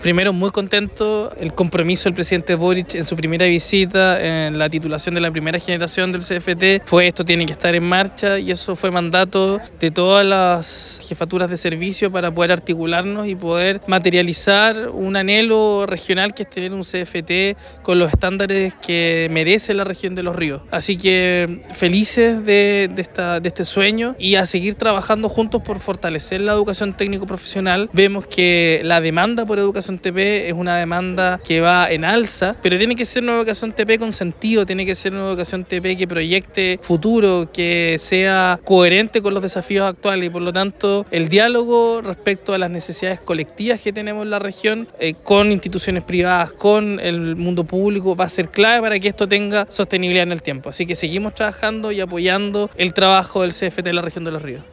Seremi-de-Educacion-Juan-Pablo-Gerter.mp3